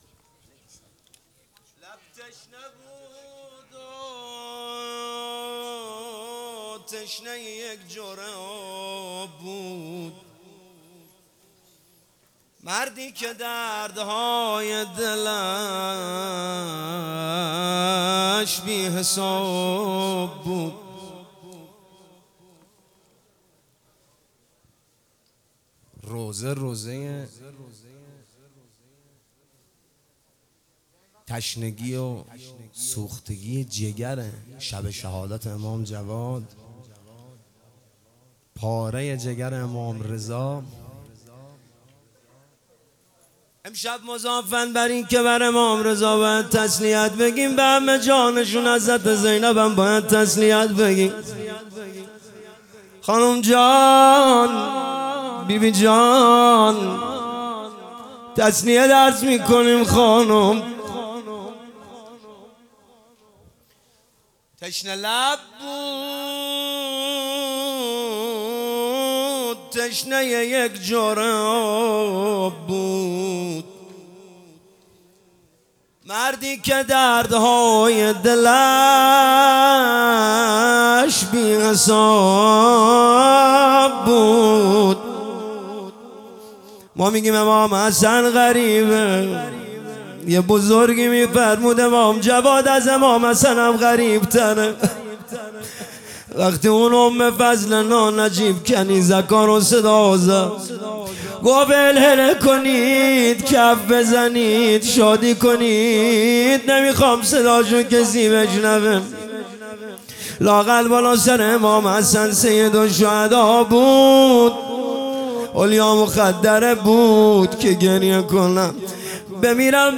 روضه امام جواد (ع)